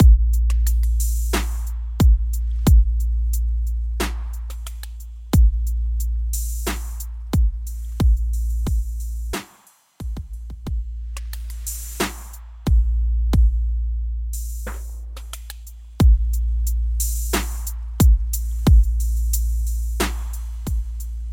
Tag: 90 bpm RnB Loops Drum Loops 3.59 MB wav Key : Unknown